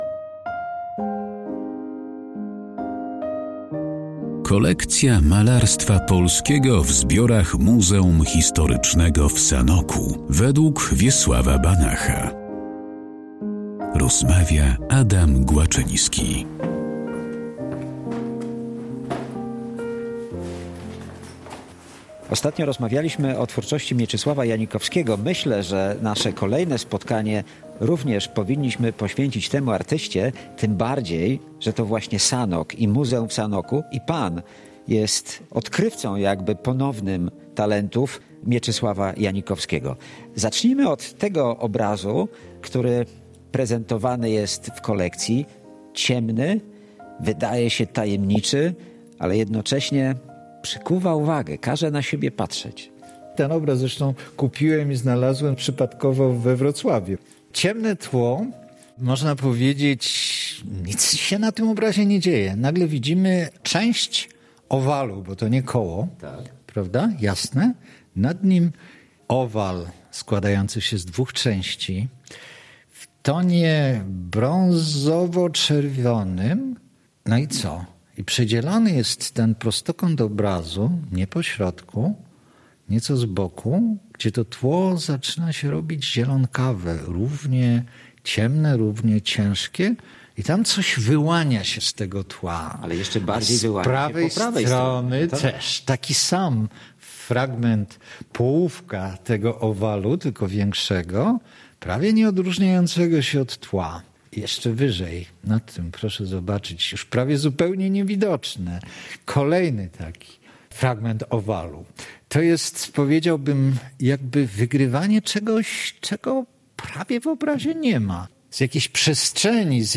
historyk sztuki